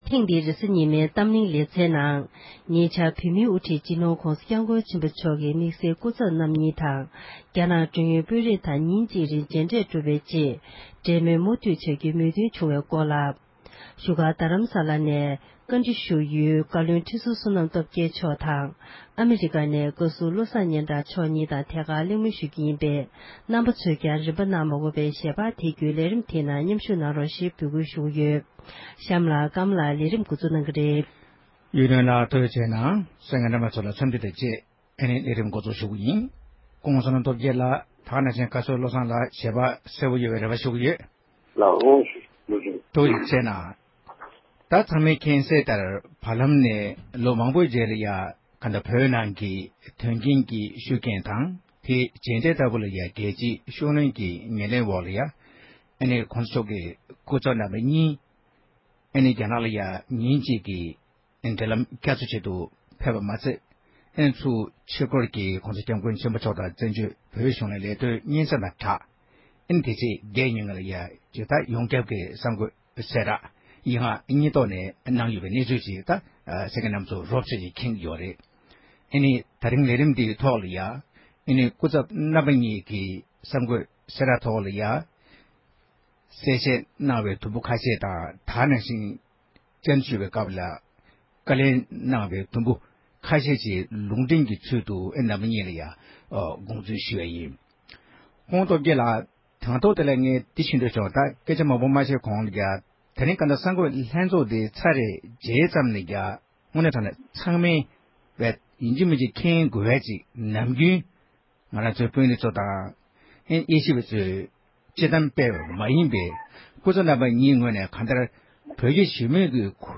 བགྲོ་གླེ་གནང་བ་ཞིག་གསན་རོགས